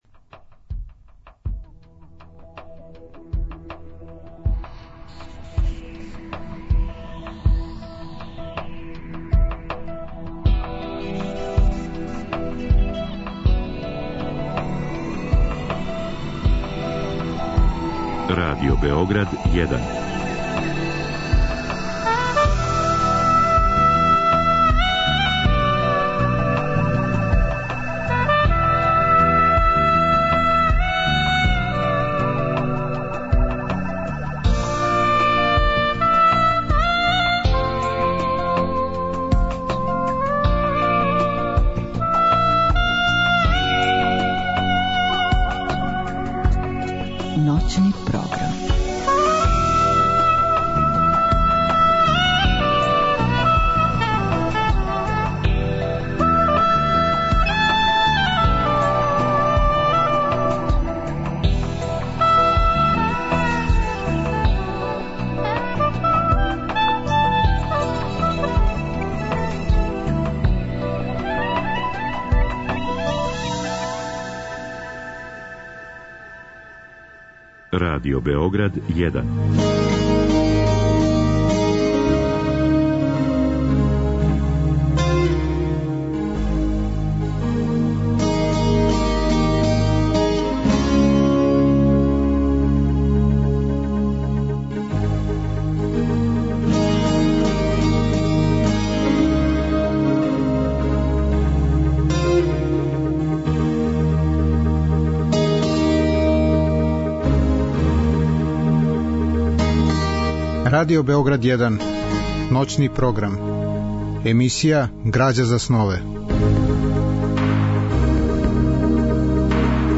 У другом делу емисије, од два до четири часa ујутро, слушаћемо делове радио-драме Михајло Идворски Пупин аутора Миливоја Анђелковића, која је реализована 1994. године у продукцији Драмског програма Радио Београда.